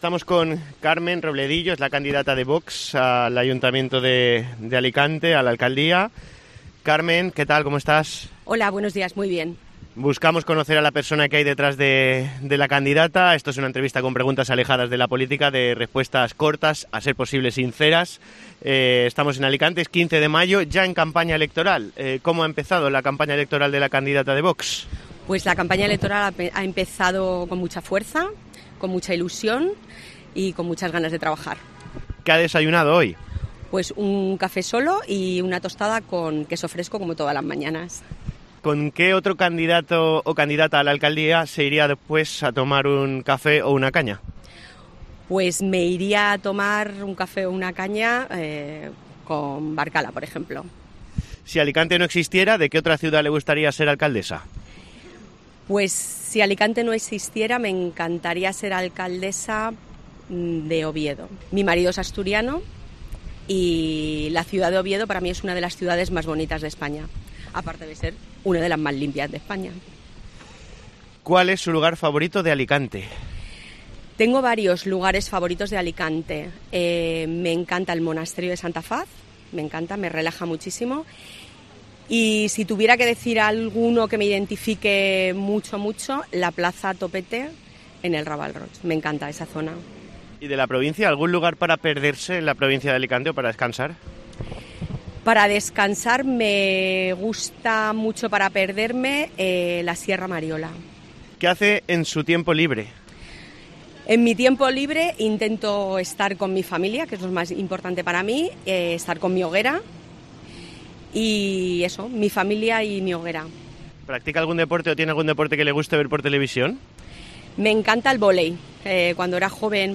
Entrevista personal a Carmen Robledillo, candidata de Vox a la Alcaldía de Alicante